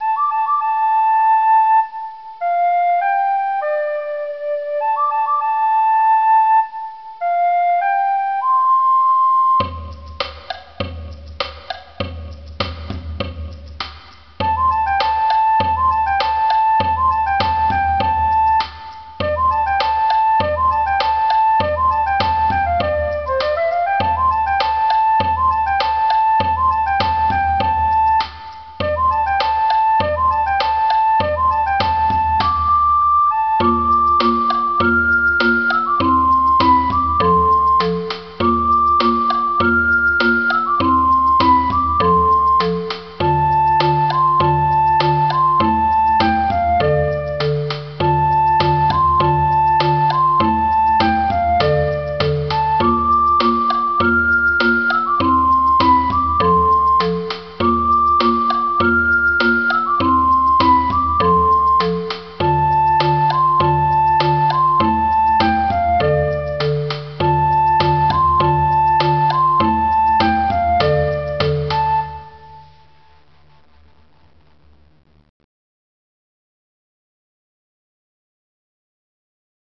Trátase dunha canción popular moi empregada no entroido, orixinal de Arxentina e que de seguro coñecedes.
Como vedes, ten un ritmo moi sinxelo e pódese tocar en grupo con instrumentos reciclados sen problema.